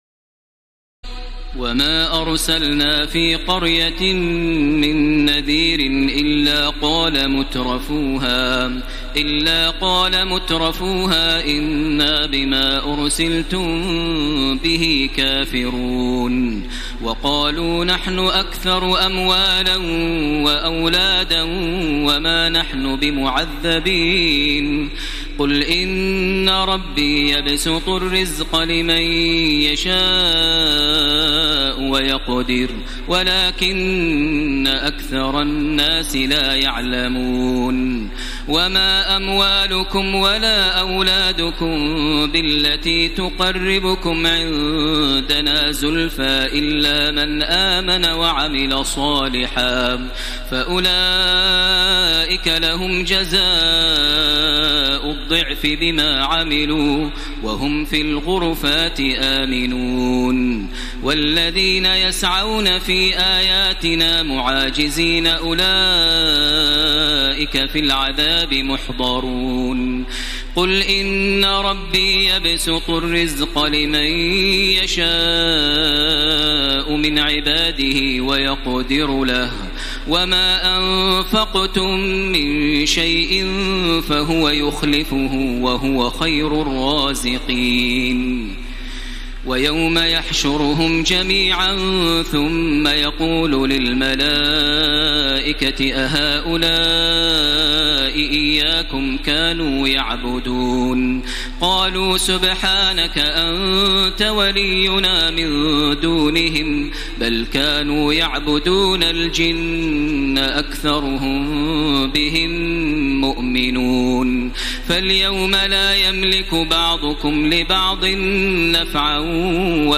تراويح ليلة 21 رمضان 1434هـ من سور سبأ (34-54) وفاطر و يس(1-32) Taraweeh 21 st night Ramadan 1434H from Surah Saba and Faatir and Yaseen > تراويح الحرم المكي عام 1434 🕋 > التراويح - تلاوات الحرمين